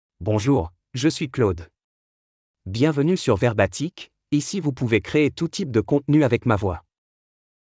MaleFrench (France)
ClaudeMale French AI voice
Voice sample
Listen to Claude's male French voice.
Claude delivers clear pronunciation with authentic France French intonation, making your content sound professionally produced.